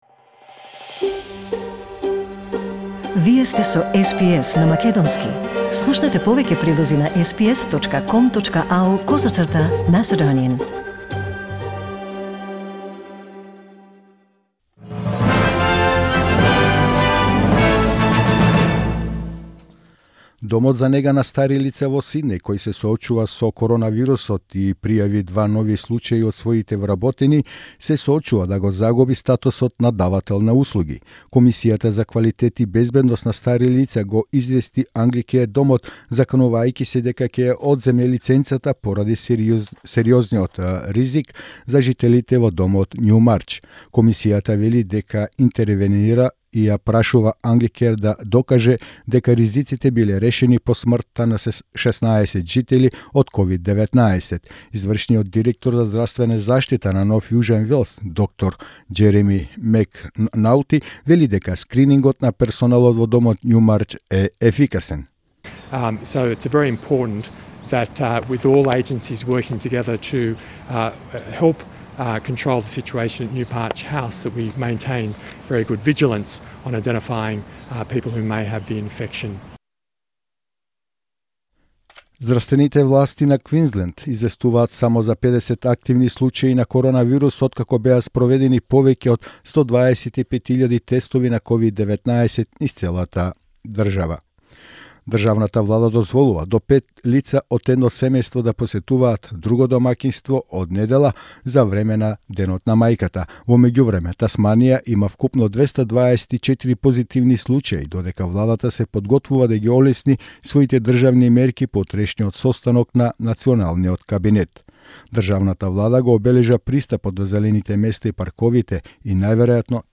SBS News in Macedonian 7 May 2020